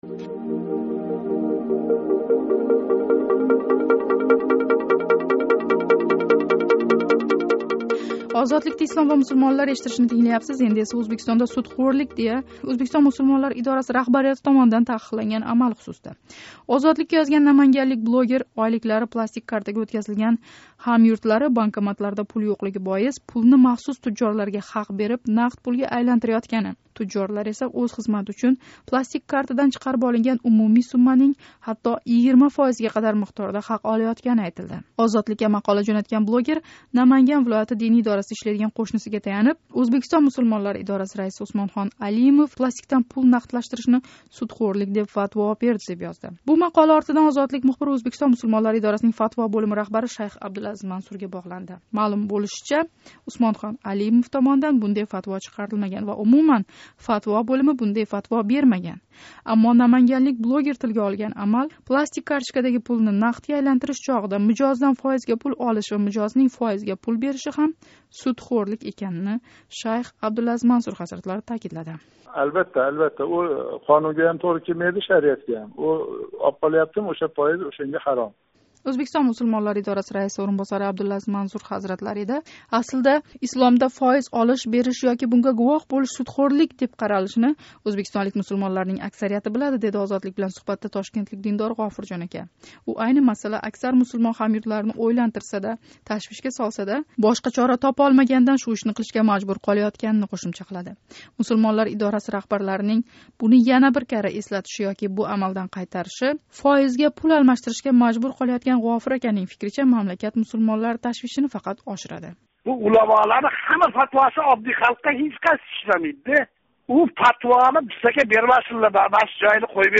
Ўзбекистонда пластикдаги пулни фоиз тўлаш эвазига нақдлаштириш судхўрлик, дея фатво берилгани ҳақидаги хабарни изоҳлаган Ўзбекистон Мусулмонлари идораси расмийси шайх Абдулазиз Мансур Озодлик тингловчилари баъзи саволларига жавоб берди. Мулозим мусулмонларнинг "фоиз" сўзи тилга олинган ҳар ҳолатдан қочиши тўғри эмас, деб тушунтириш берди.